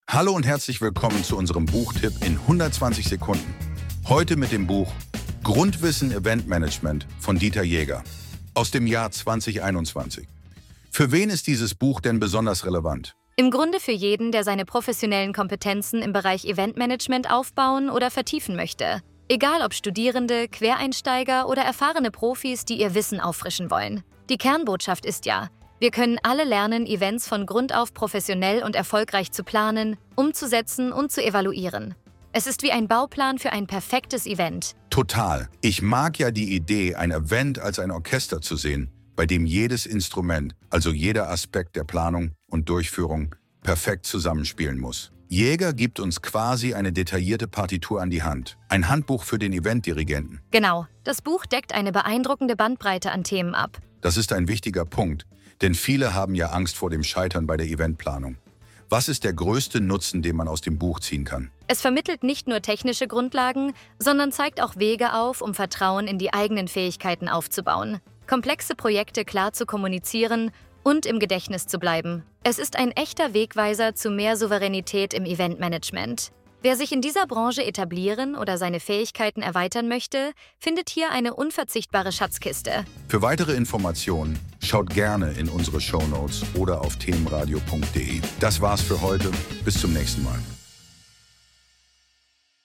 Buchtipp-Grundwissen-Eventmanagement-AI-Final.mp3